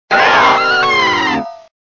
Fichier:Cri 0483 DP.ogg